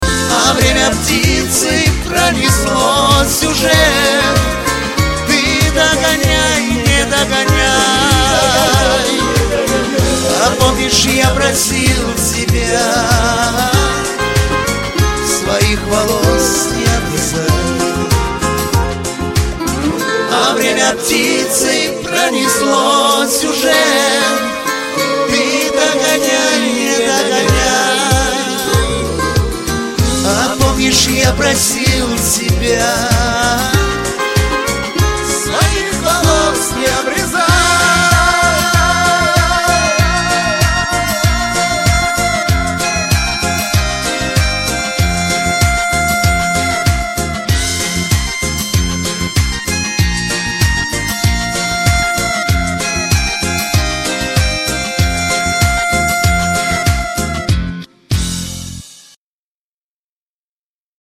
• Качество: 320, Stereo
мужской вокал
грустные
русский шансон
лиричные